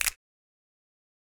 Snap Groovin 1.wav